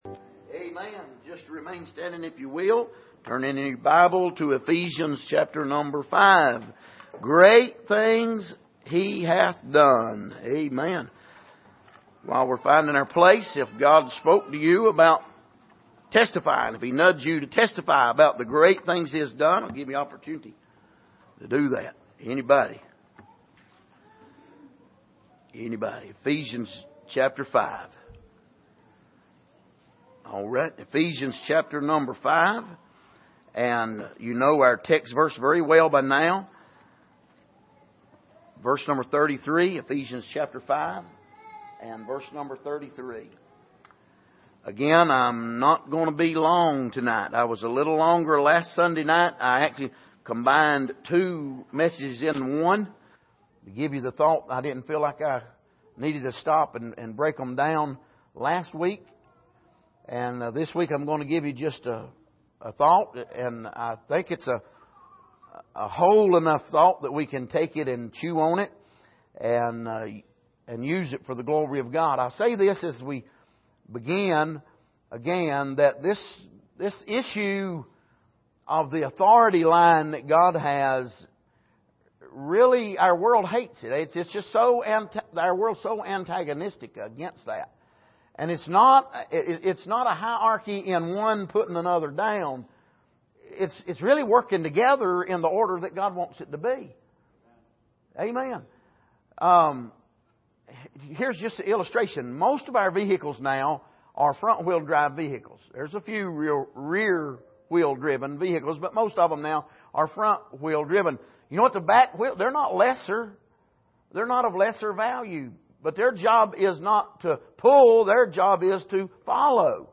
Passage: Ephesians 5:33 Service: Sunday Evening